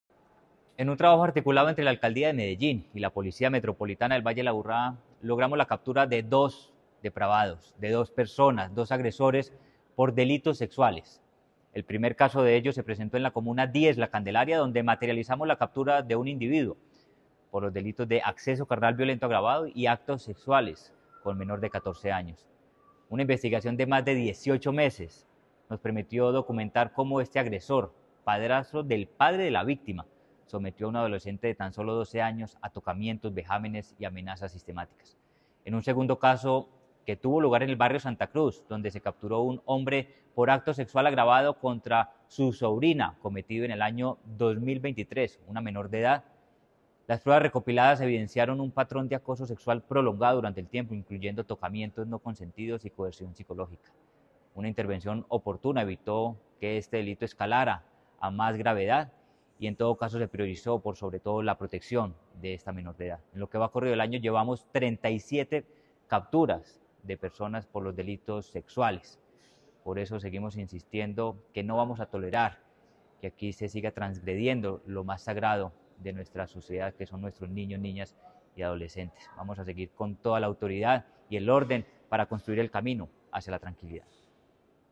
Audio Palabras de Manuel Villa Mejía, secretario de Seguridad y Convivencia En un operativo conjunto entre la Alcaldía de Medellín y la Policía Metropolitana del Valle de Aburrá se logró la captura de dos personas vinculadas a delitos sexuales.